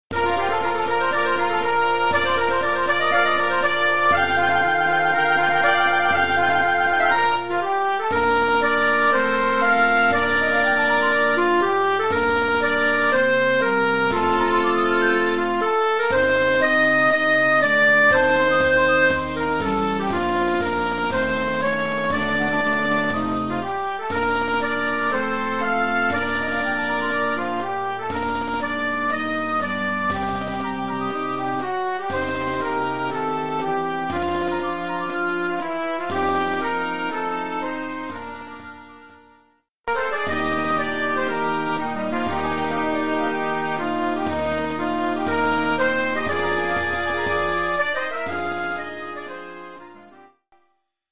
Collection : Harmonie (Marches)
Marche-parade pour
harmonie-fanfare, avec
tambours et clairons ad lib.